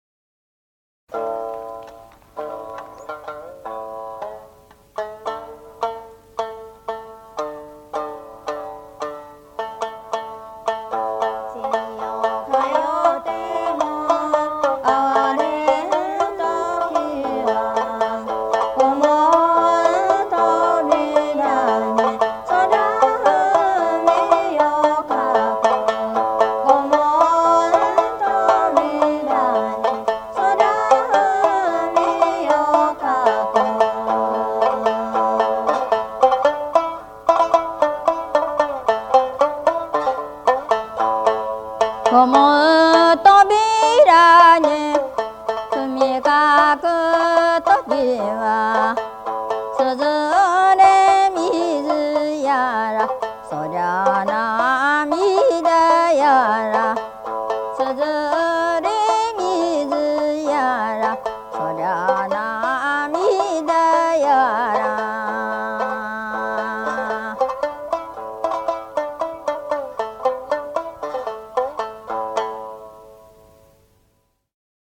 これぞ日本のフォーク＆ブルース、第2弾！